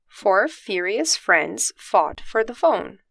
Mas não se preocupe: os áudios para que você escute as pronúncias corretas e pratique todos eles estão presentes para te ajudar, como sempre!
Nosso próximo trava-línguas é bem curtinho, mas bem útil para praticar a pronúncia do “f” e do “ph”: